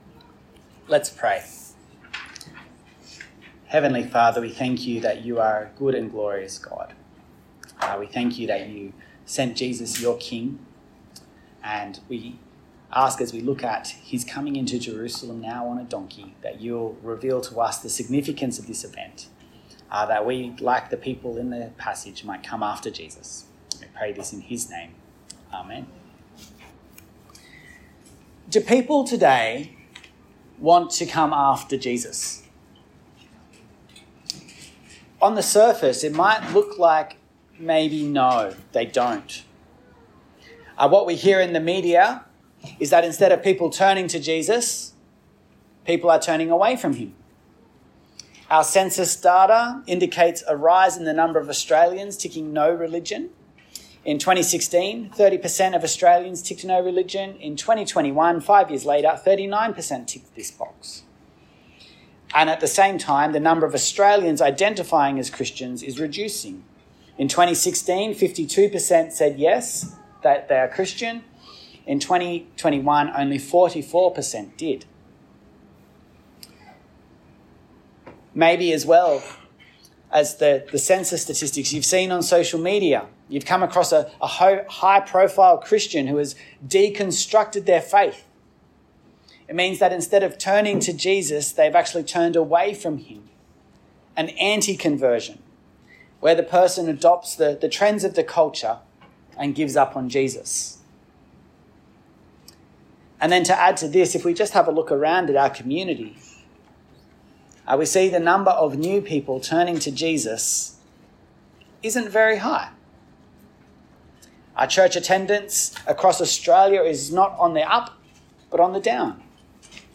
A message from the series "One Off Sermons." 2000 years ago Jesus entered Jerusalem, and people proclaimed him as God's long expected King. What does this mean today and do people still come to Jesus?